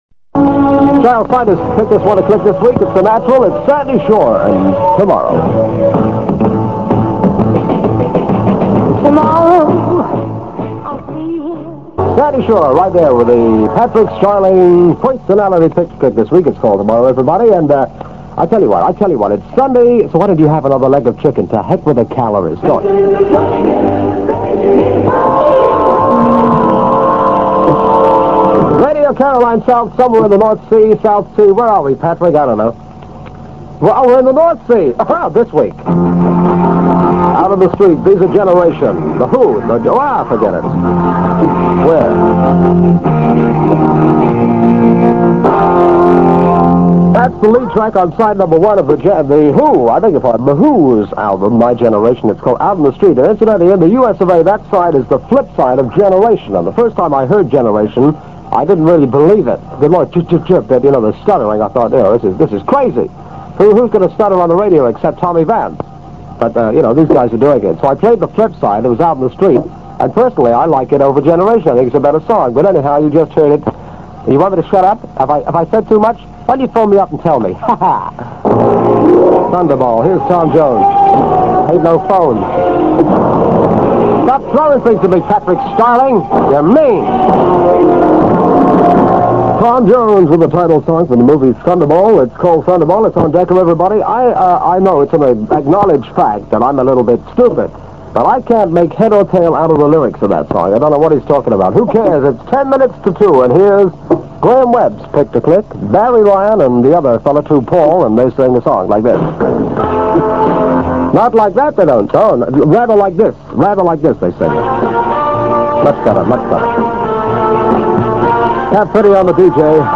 click to hear audio Tommy Vance on Radio Caroline South in January 1966, near the beginning of his time with the station.